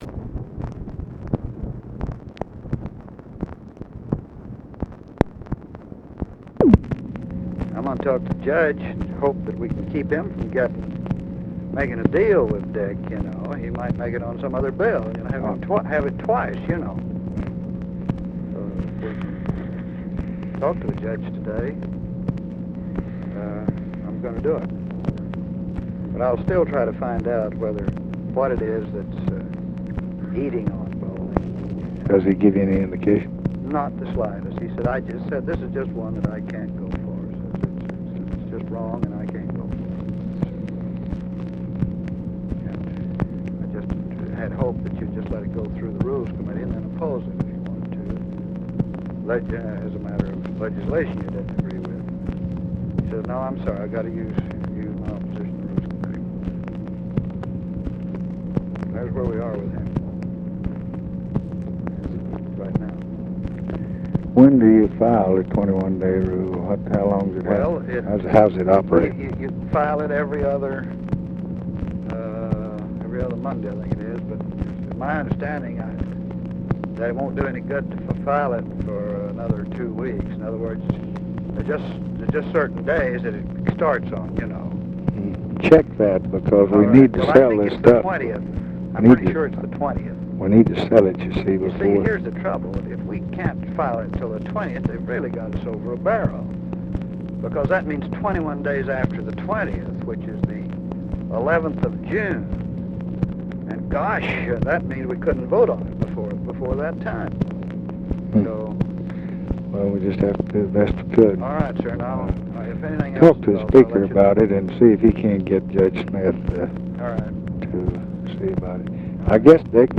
Conversation with CARL ALBERT, May 3, 1966
Secret White House Tapes